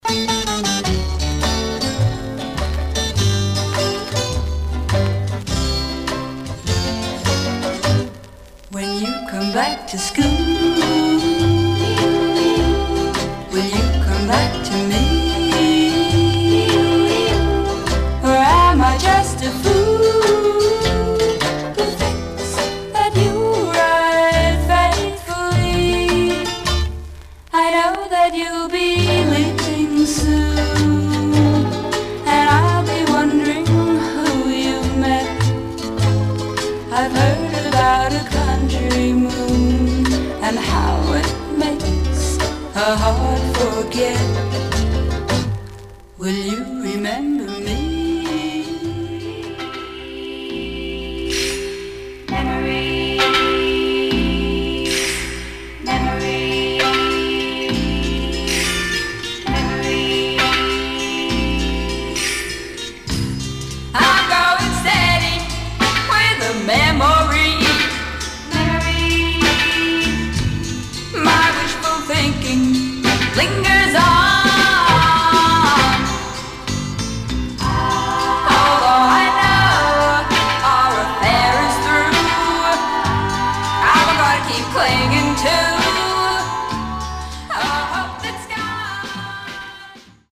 Some surface noise/wear
Mono
White Teen Girl Groups